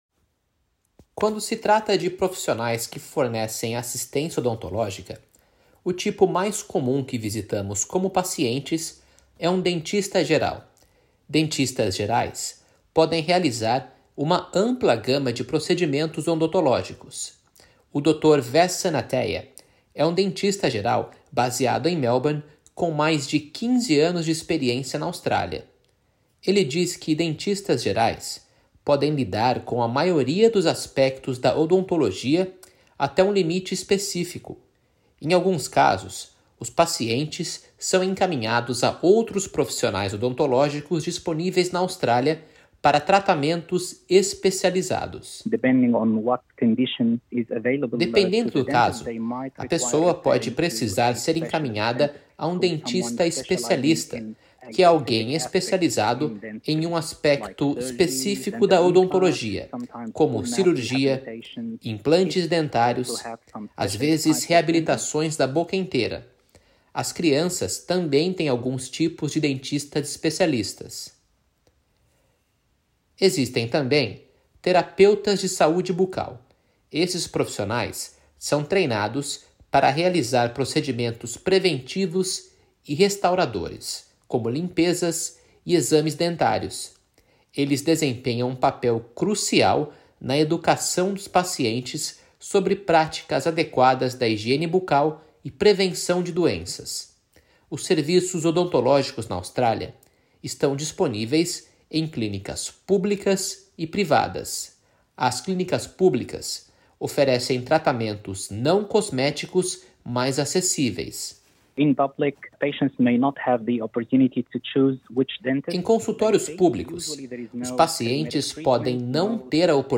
Nesta reportagem, abordaremos os princípios básicos da assistência odontológica para adultos e crianças na Austrália, principalmente como acessar esses serviços, os custos envolvidos e algumas dicas essenciais de saúde bucal.